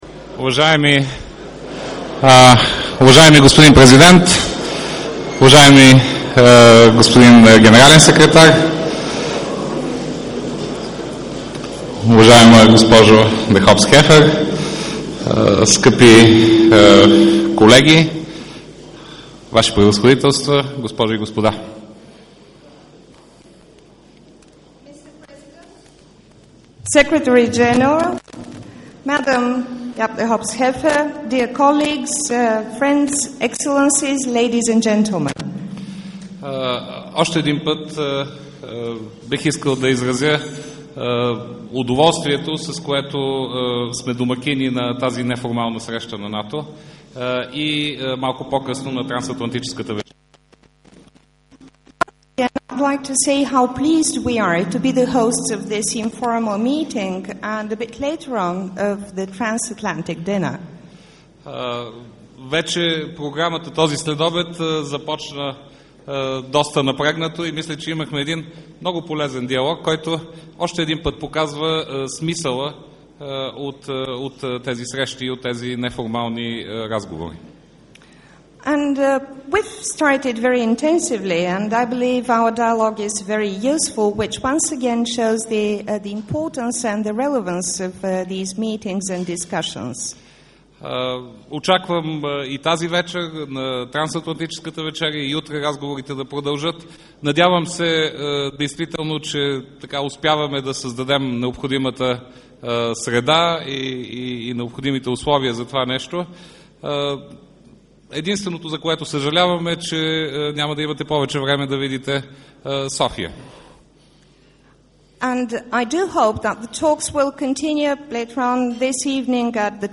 Opening remarks at the welcoming reception for Ministers and participants at the National History Museum, Sofia